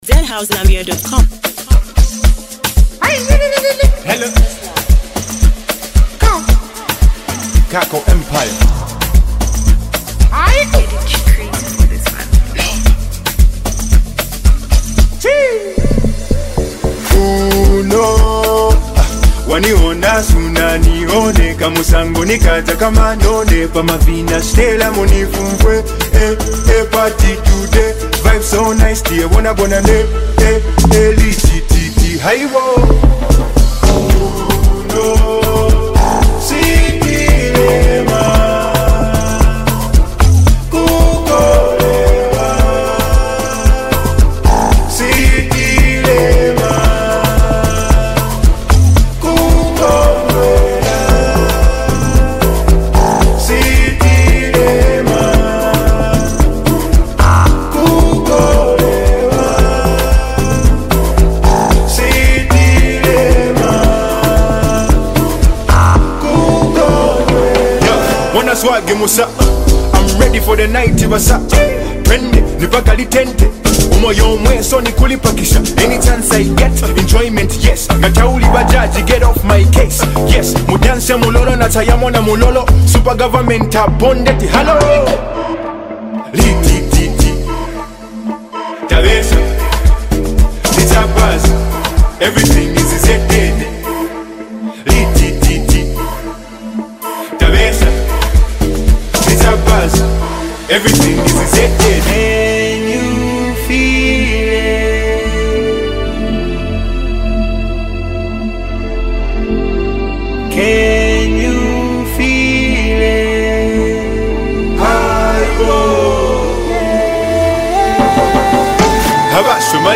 a powerful track packed with vibes